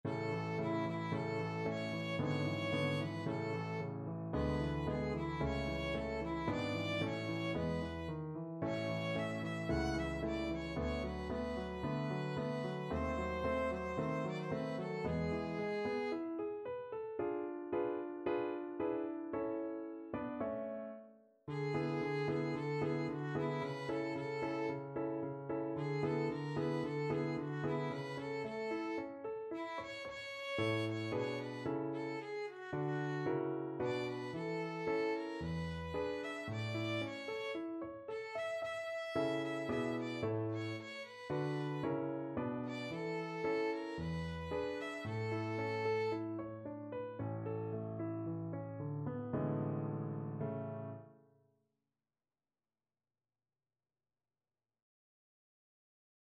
Violin
A major (Sounding Pitch) (View more A major Music for Violin )
~ = 56 Affettuoso
2/4 (View more 2/4 Music)
E5-F#6
Classical (View more Classical Violin Music)